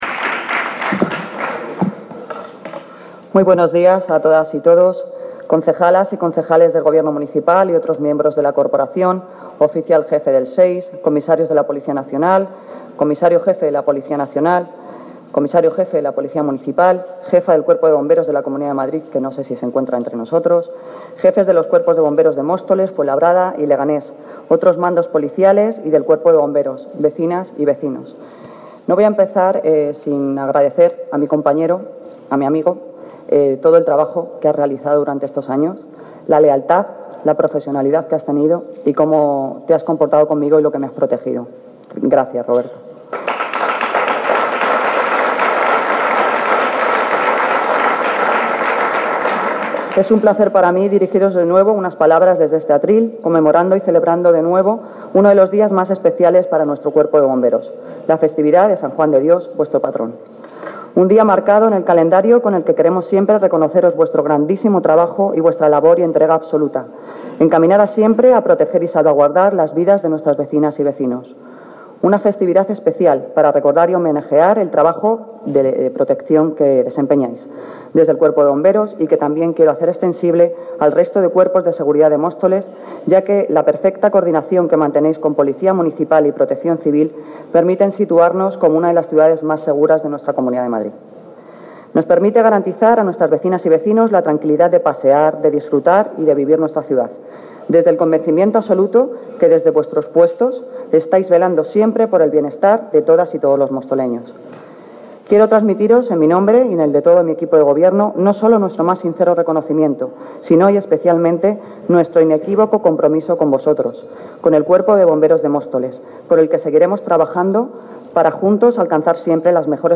Los bomberos de Móstoles celebran la Festividad de su Patrón con la entrega de reconocimientos y diplomas
Audio - Noelia Posse (Alcaldesa de Móstoles) Festividad Patrón Bomberos